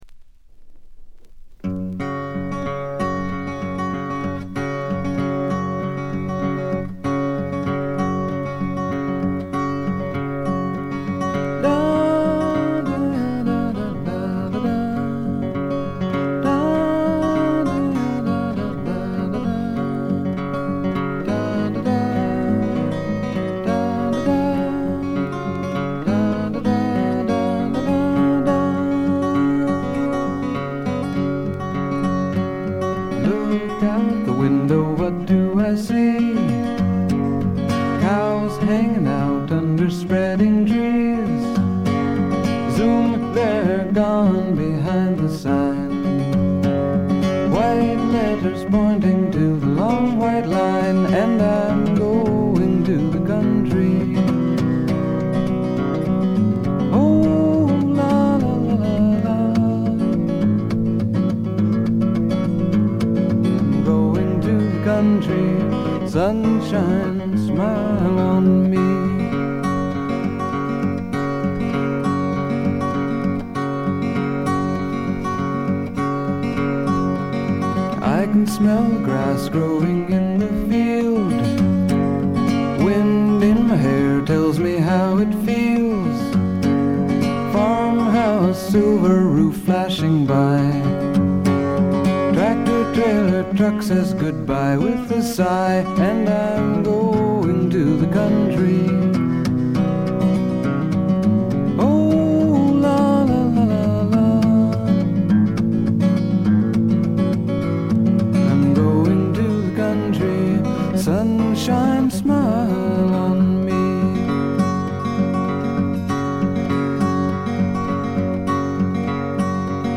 部分試聴ですが、微細なチリプチごくわずか。
試聴曲は現品からの取り込み音源です。